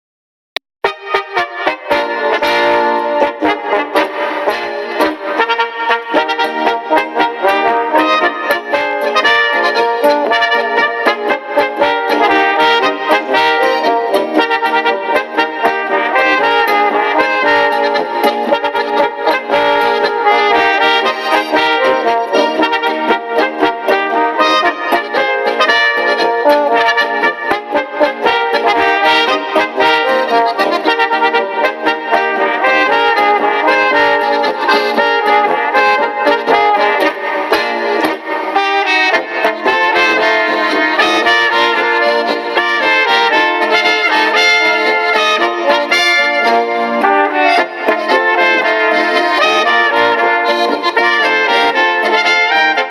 Gattung: Tanzlmusi
Besetzung: Volksmusik Tanzlmusik